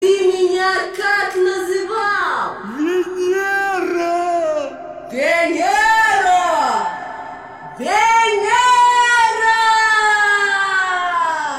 из аудиопостановки "мастер и маргарита",диалог наташи-домработницы и николая ивановича,в полёте на реку